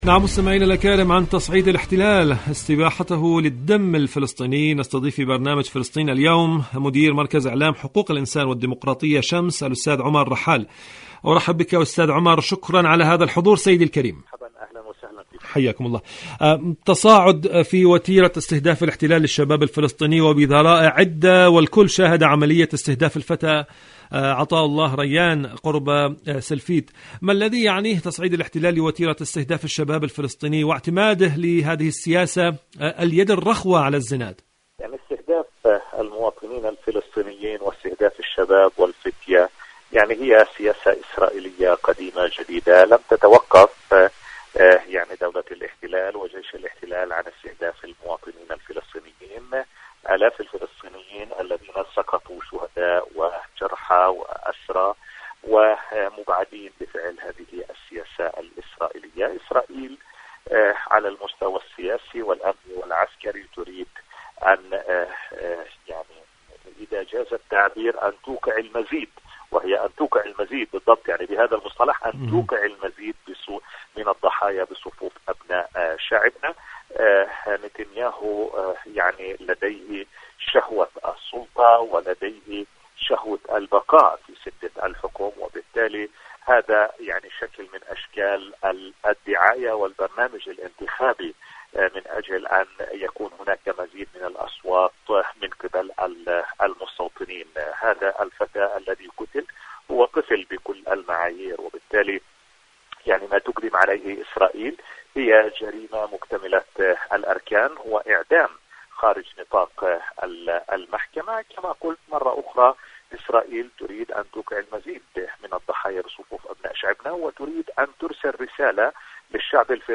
مقابلات إذاعية برنامج فلسطين اليوم